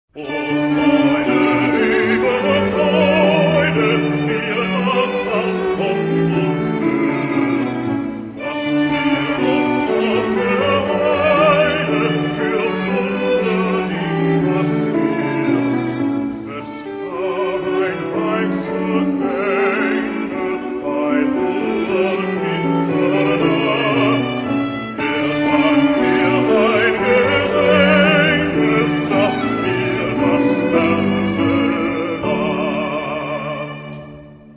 Genre-Stil-Form: geistlich
Charakter des Stückes: religiös ; besinnlich ; choralartig
Chorgattung: SATB  (4 gemischter Chor Stimmen )
Instrumente: Melodieinstrument (ad lib) ; Tasteninstrument (1)
Tonart(en): D-Dur